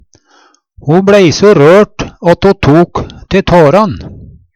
ta te tåran - Numedalsmål (en-US)